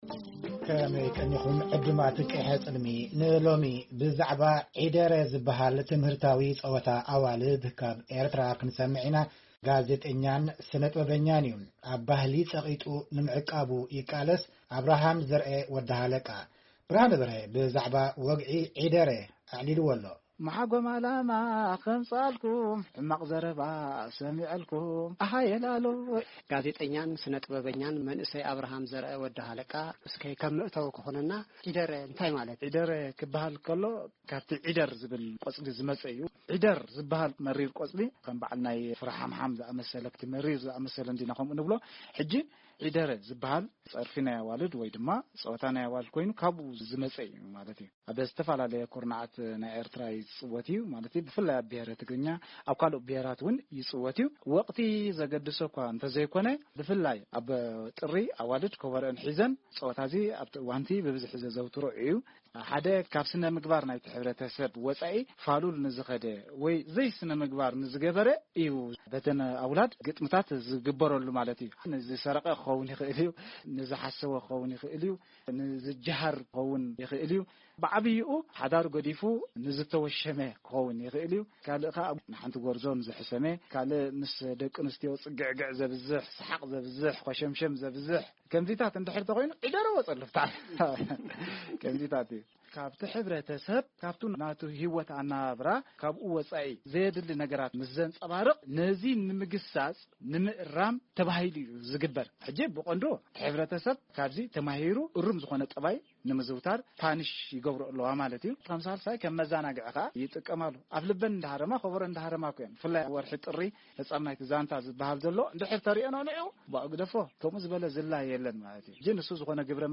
ዕላል